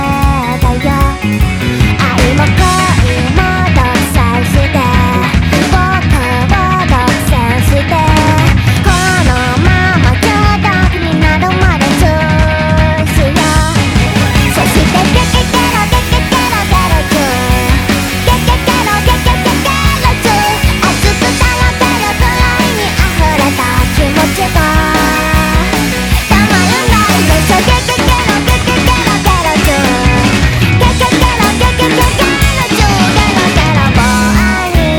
Жанр: J-pop / Поп